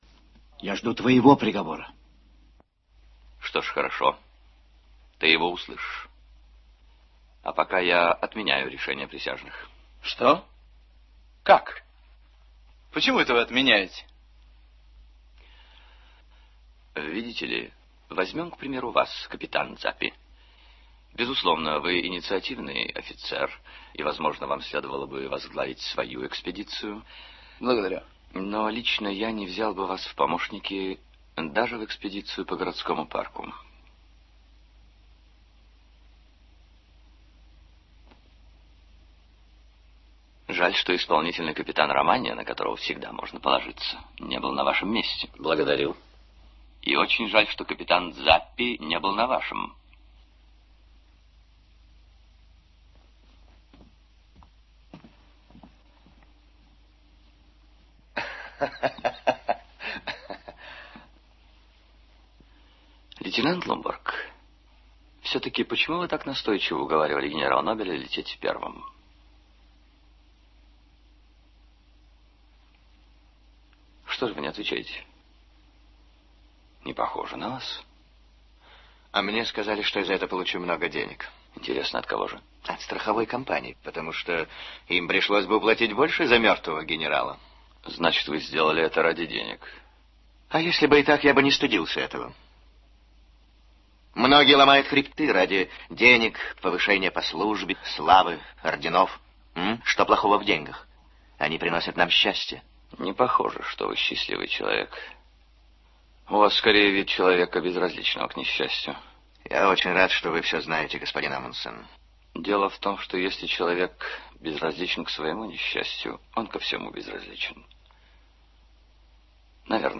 Послушаем фонограмму одной из финальных сцен «Красной палатки». Амундсен говорит о том, что никто из присутствующих не может считать себя вправе осуждать генерала Нобиле: